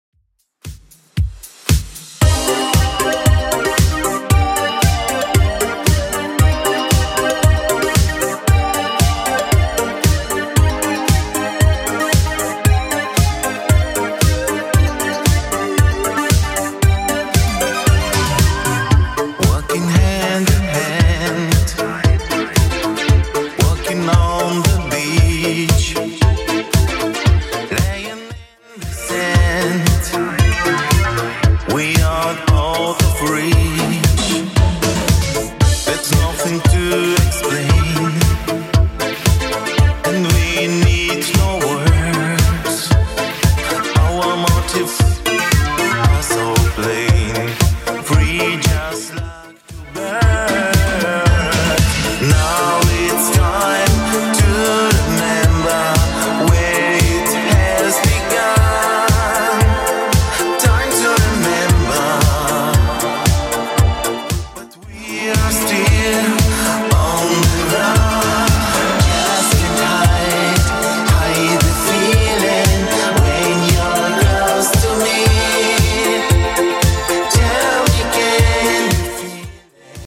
Genre: 90's Version: Clean BPM: 90